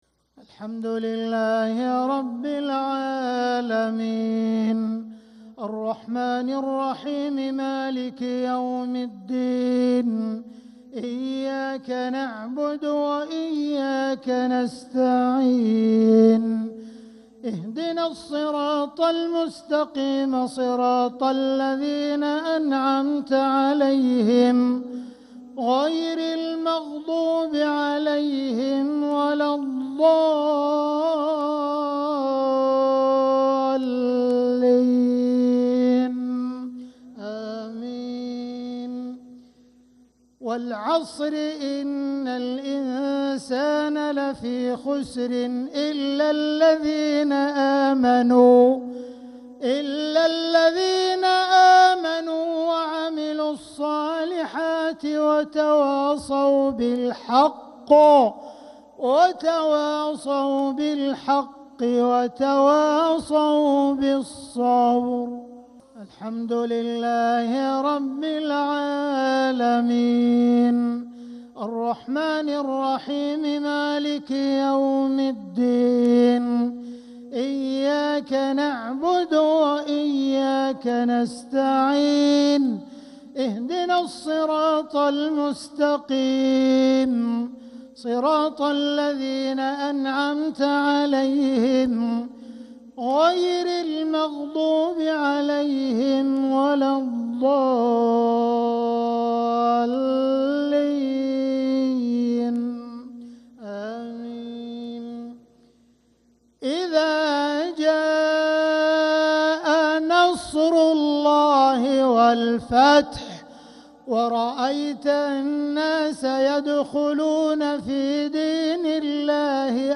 صلاة الجمعة 9 محرم 1447هـ سورتي العصر و النصر كاملة | Jumu’ah prayer from Surah Al-Asr and Al-Ansr 4-7-2025 > 1447 🕋 > الفروض - تلاوات الحرمين